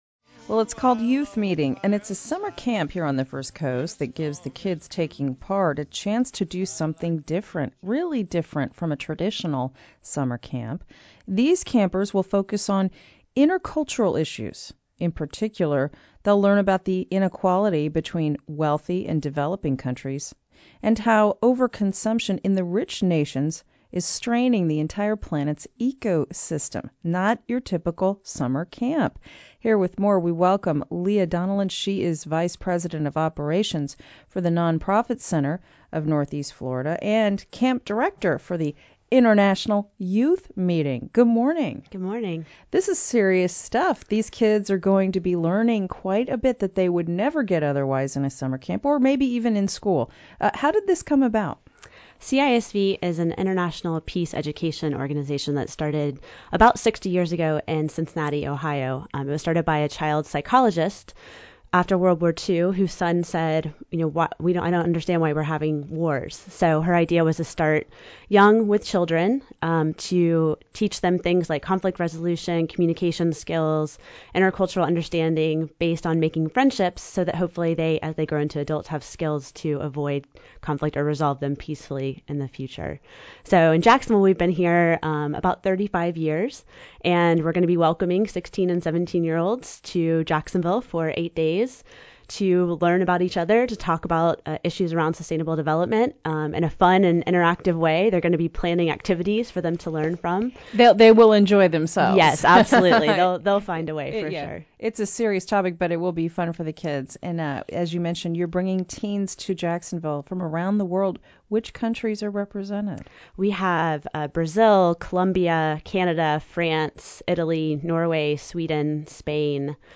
CISV: WJCT First Coast Connect Interview